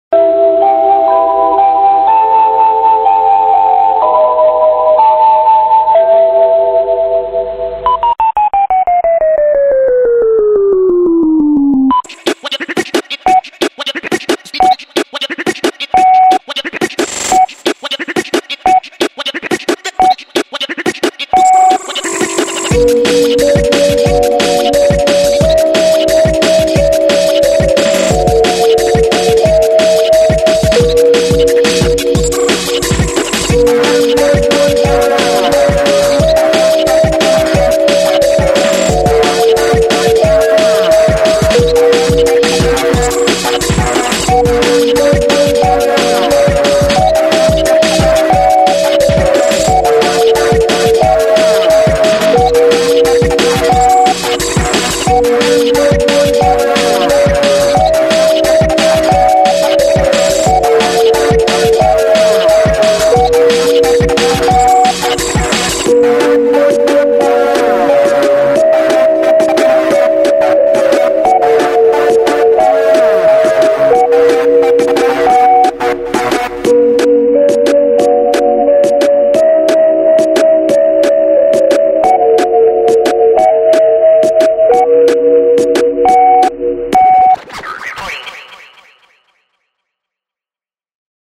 • Качество: 128, Stereo
электронные
скретч